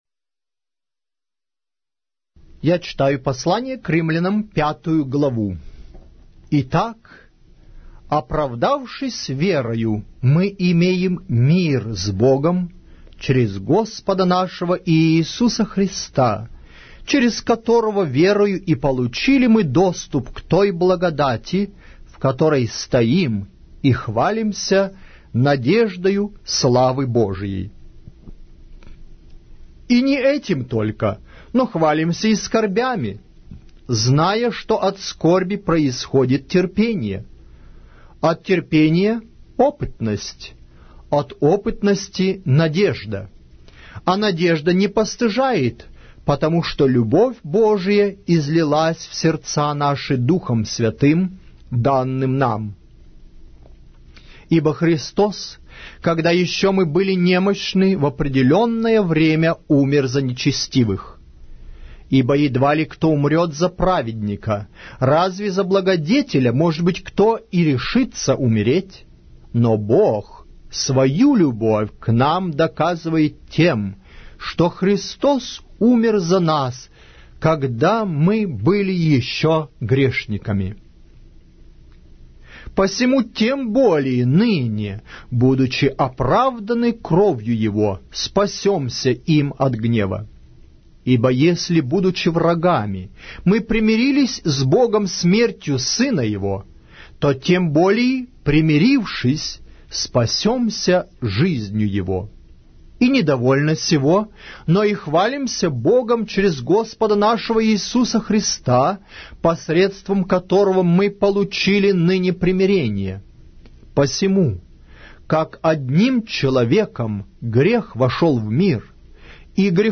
Автор аудиокниги: Аудио - Библия